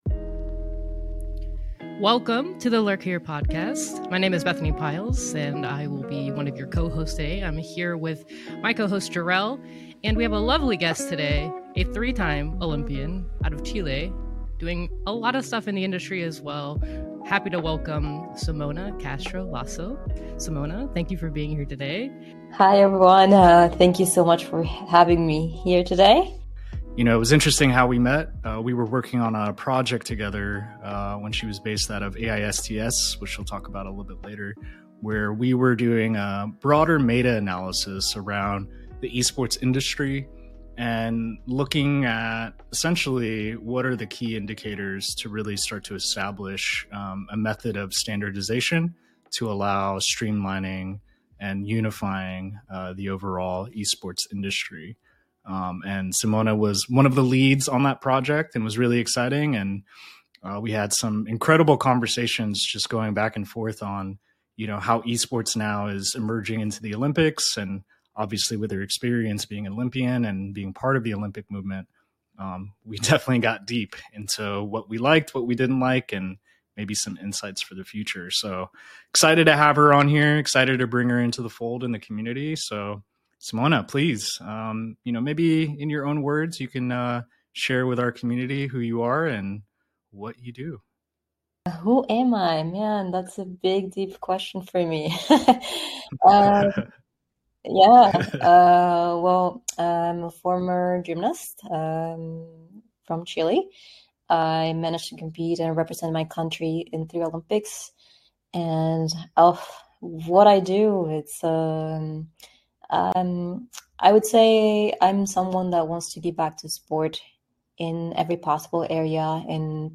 Tune in for a conversation on resilience, performance, and the future of competition.